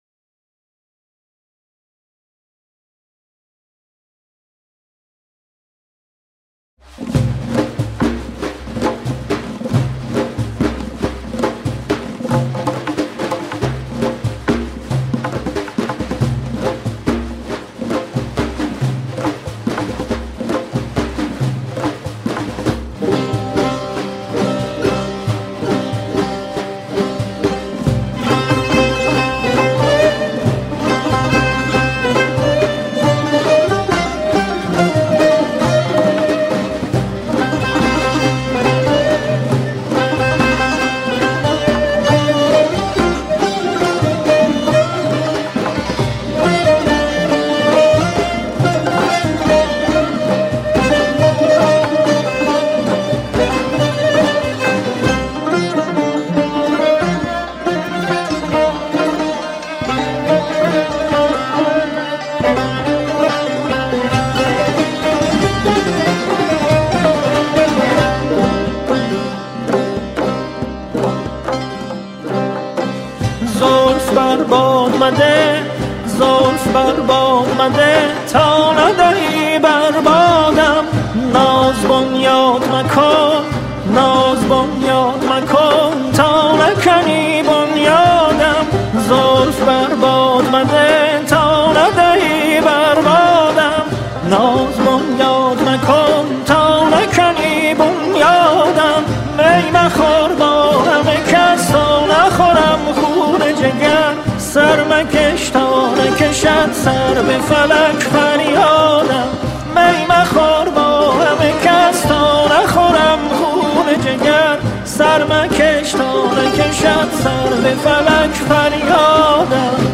کمانچه
تار و عود
سنتور
دف و دایره
تمبک